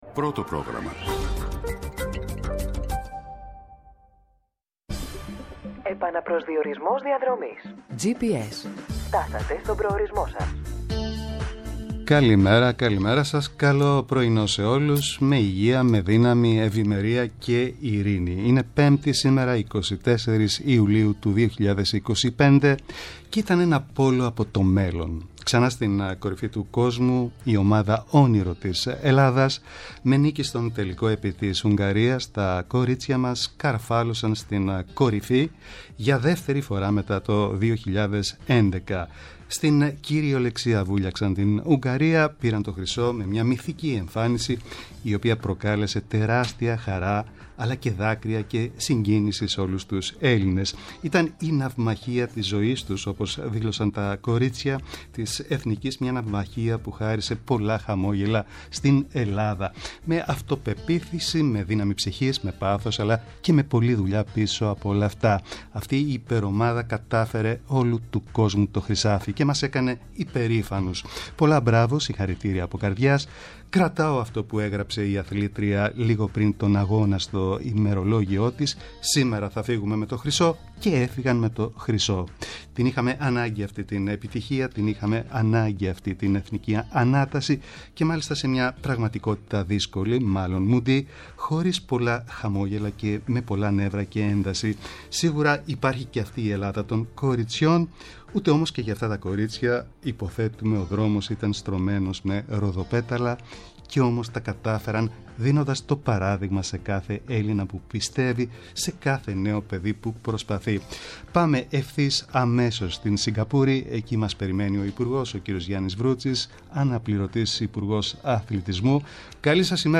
-O Γιάννης Βρούτσης, αναπληρωτής υπουργός αθλητισμού, για τον θρίαμβο του γυνειακείου πόλο
-Ο Μάνος Λογοθέτης, γενικός γραμματέςα μεταναστευτικής πολιτικής